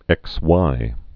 (ĕkswī)